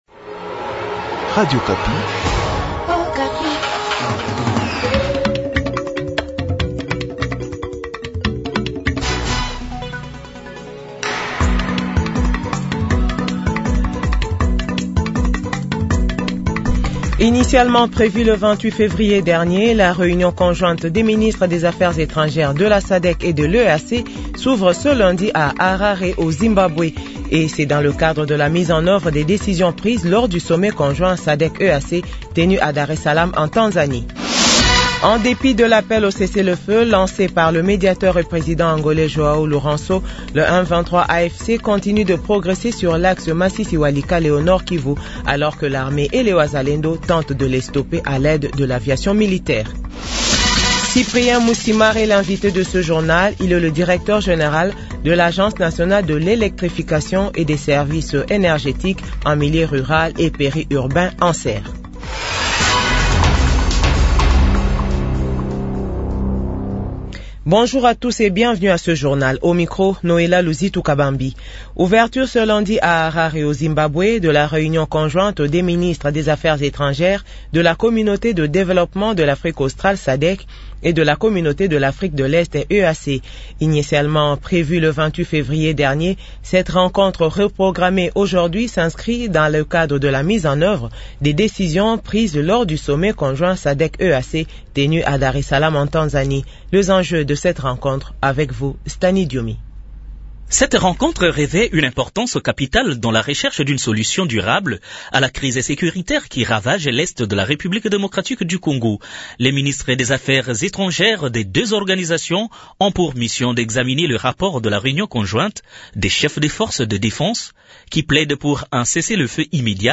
Journal 12h